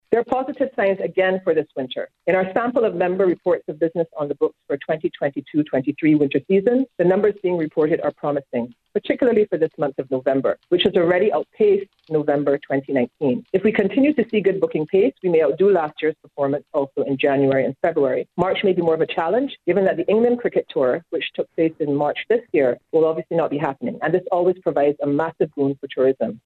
during a media briefing this morning